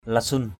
/la-sun/ (cv.) lisun l{s~N, lathun lE~~N
lasun.mp3